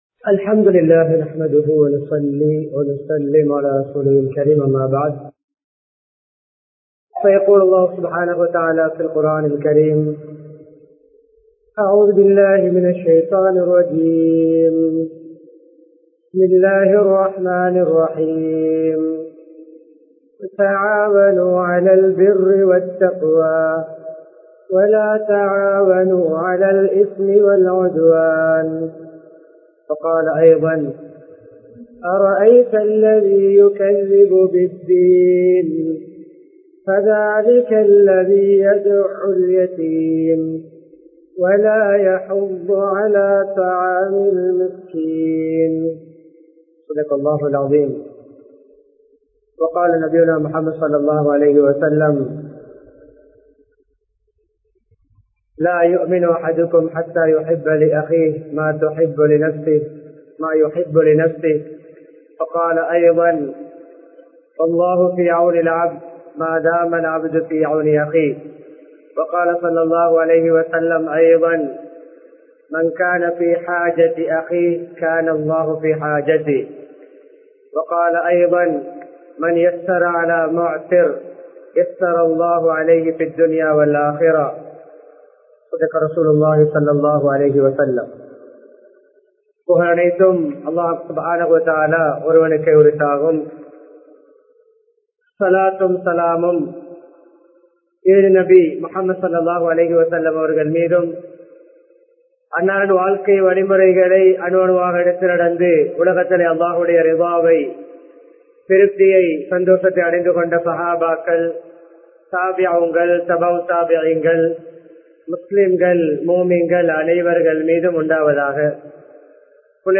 மறுமைக்கான சேகரிப்பு | Audio Bayans | All Ceylon Muslim Youth Community | Addalaichenai
Muhiyadeen Jumua Masjith